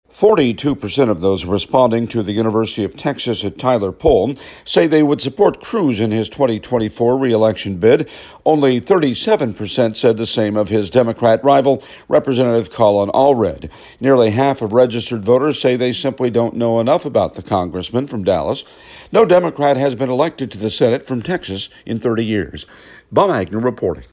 Reporting from Capitol Hill